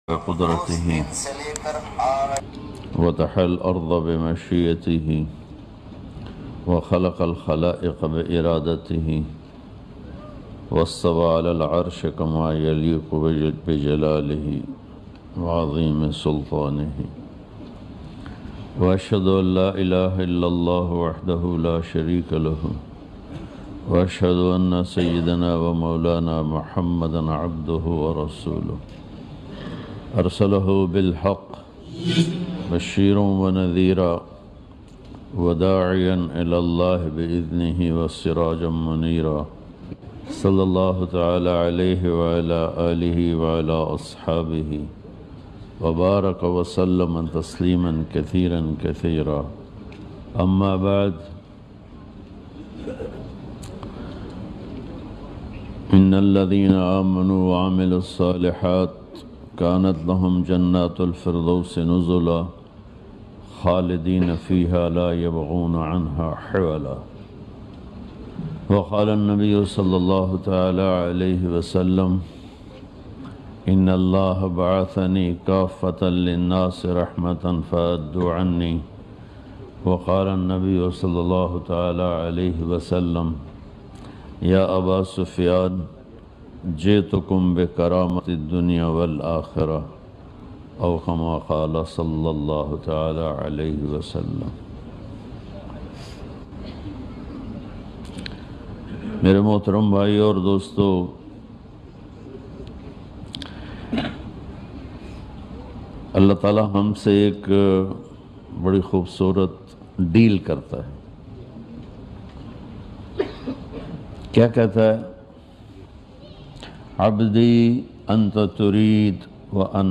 Molana tariq jameel bayan lattest mp3